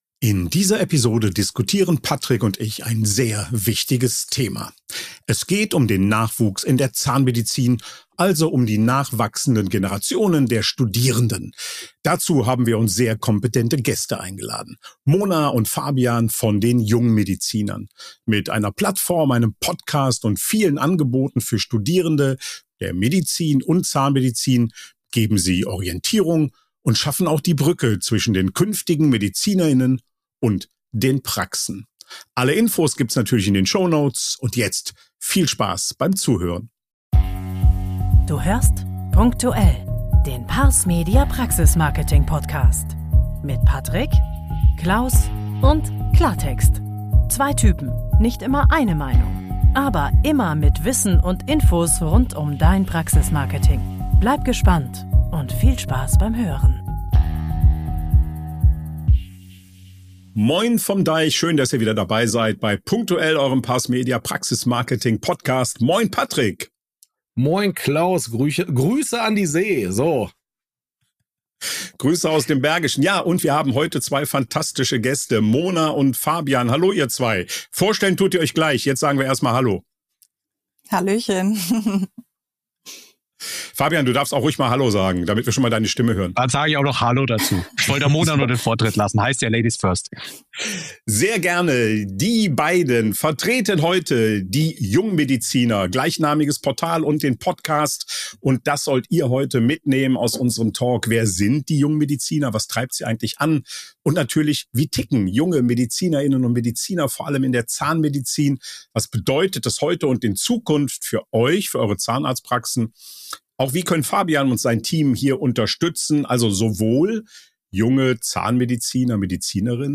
Nachwuchs für die Zahnarztpraxis: Talk mit "Die Jungmediziner" | #120 ~ punk.tuell: Der Marketing-Podcast für die Zahnarztpraxis Podcast